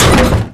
collisions
car_heavy_6.wav